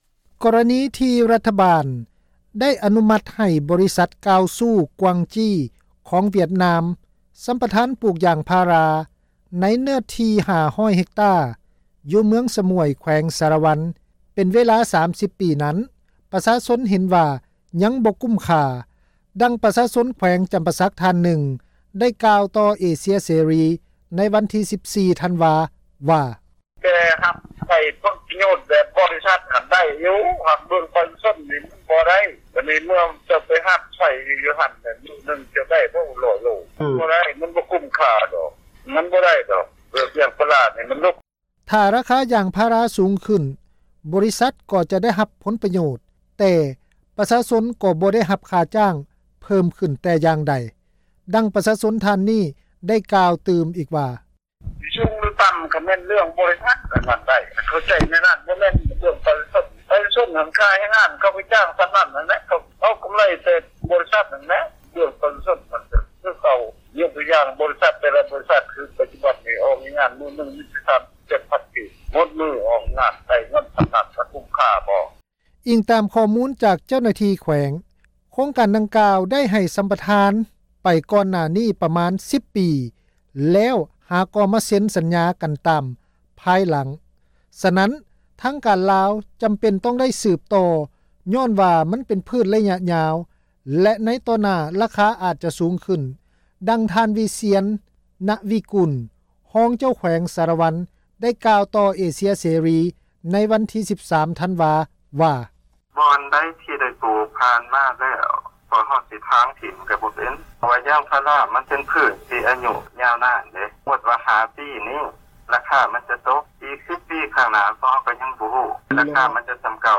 ພາຍຫຼັງທີ່ອະນຸມັດໄປແລ້ວ ຖ້າໂຄງການໃດມີປະສິດທິຜົນ ກໍຈະໃຫ້ສືບຕໍ່ ແຕ່ຖ້າບໍ່ມີປະສິດທິຜົນ ແຂວງກໍຈະລຶບລ້າງ. ດັ່ງ ທ່ານ ວິຊຽນ ຮອງເຈົ້າແຂວງສາລະວັນ ກ່າວຕໍ່ເອເຊັຍເສຣີ ອີກວ່າ: